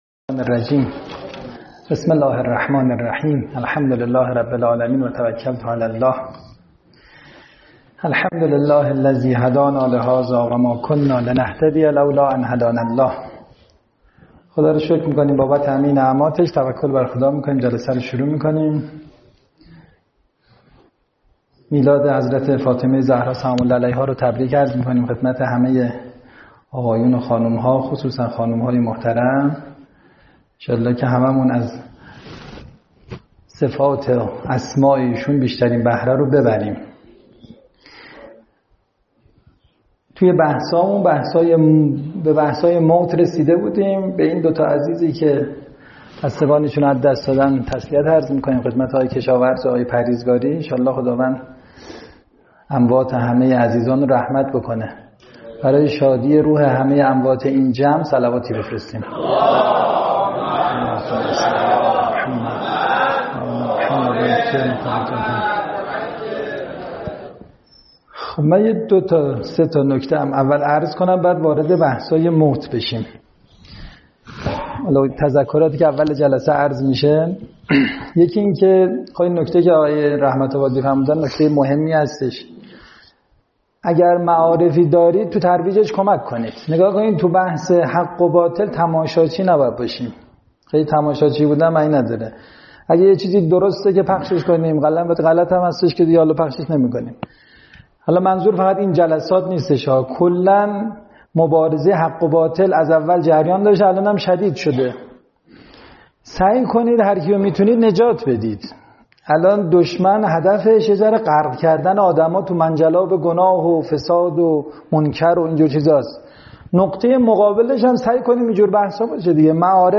سخنرانی های